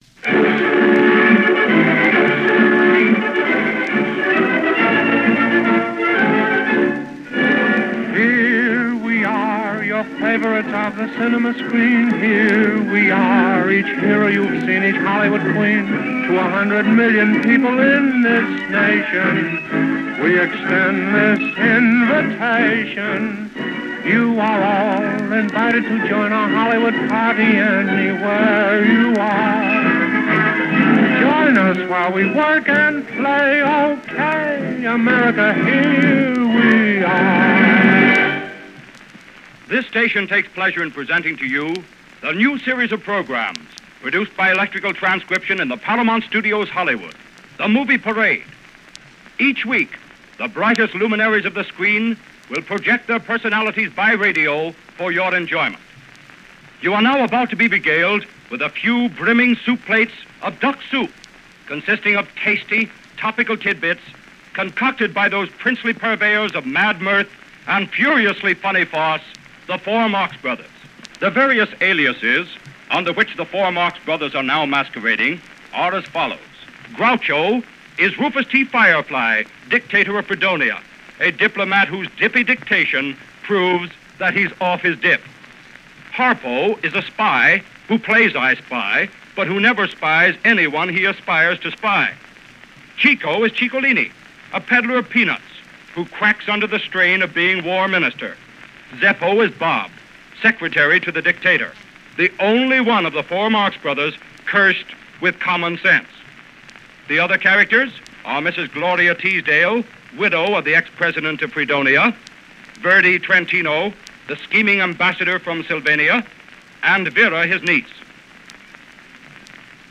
The Marx Brothers On The Radio - 1933 - Past Daily Weekend Gallimaufry - Paramount Movie Parade - Opening broadcast.
This radio version of Duck Soup marks the premier of what was a new idea for Hollywood; capturing and distilling in the space of 15 minutes a newly released film – offering a preview of coming attractions, as it were, to the radio audience.